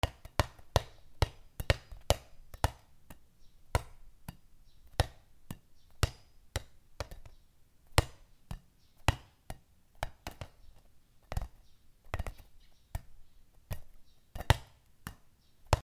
ゴムボールあそび
『ポコポコ』